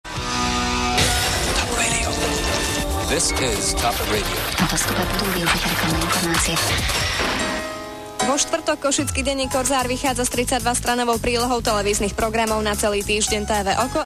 V maličkom regionálnom rádiu sa mi zdajú komplet anglické džingle ako zlý vtip.